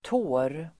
Uttal: [tå:r]